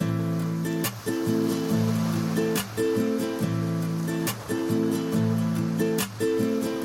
لوپ 4/4 رومبا 1 گیتار نایلون | هنر صدا
لوپ 4/4 رومبا 1 گیتار نایلون مجموعه 48 آکورد مینور , ماژور و سون به صورت Left و Right می باشد.